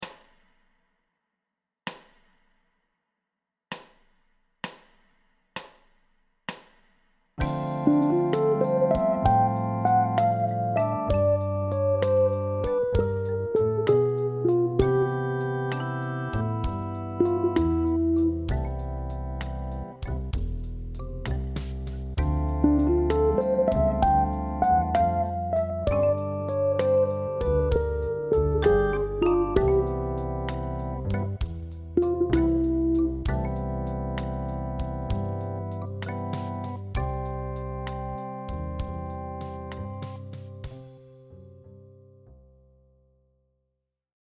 Phrase de Charlie Parker sur Donna Lee, jouée sur la position de F ∆.